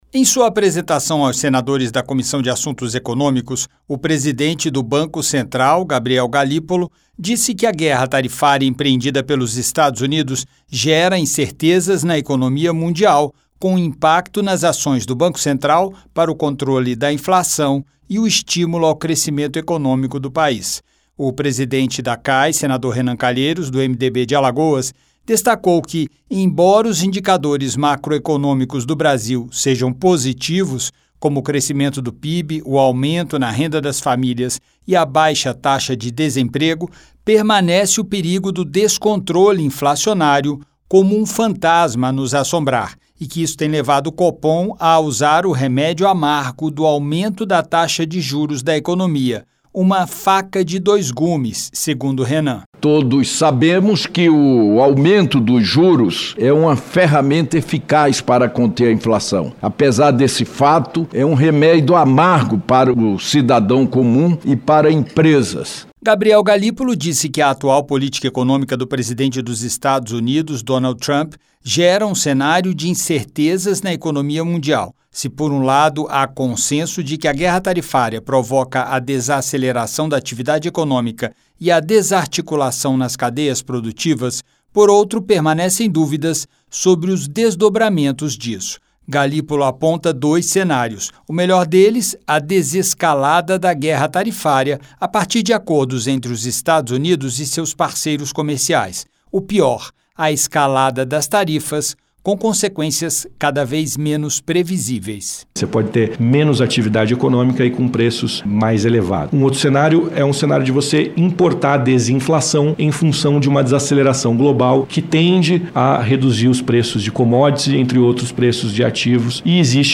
O presidente do Banco Central, Gabriel Galípolo, explicou aos senadores da Comissão de Assuntos Econômicos (CAE) as ações para o controle da inflação e o estímulo ao crescimento econômico. Para justificar o aumento da taxa de juros, Galípolo disse que o país vive um momento de incertezas e de aversão a riscos no cenário internacional, diante da guerra tarifária empreendida pelos Estados Unidos, o que exige um aumento do "prêmio" para atrair investidores ao Brasil.